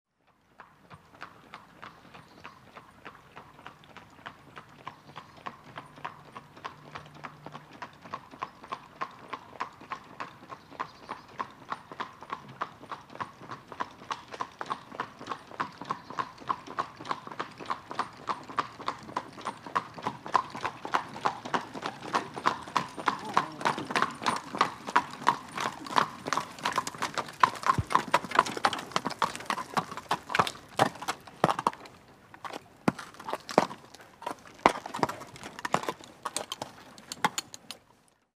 На этой странице собраны натуральные звуки телег и повозок: скрип деревянных колес, цоканье копыт лошадей, шум движения по грунтовой дороге или брусчатке.
Звук Двуколка приближается и останавливается (стук копыт) (00:38)